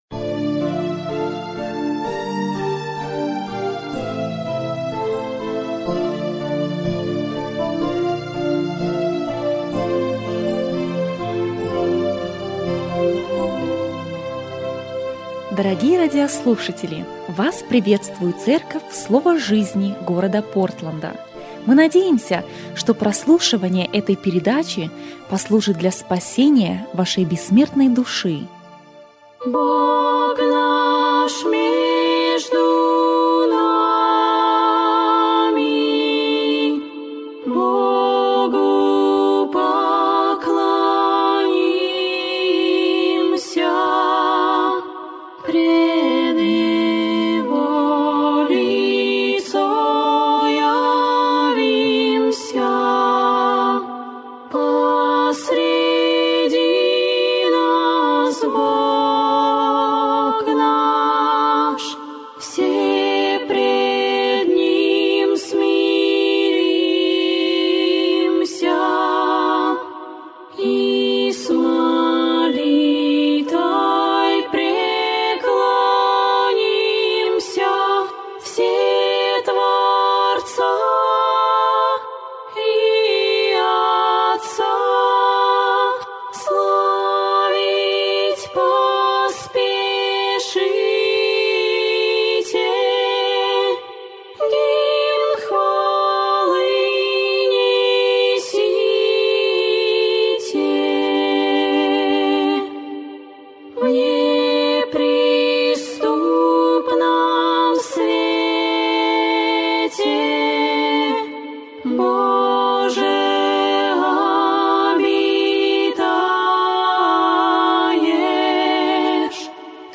Радио Передача: Признаки Второго Пришествия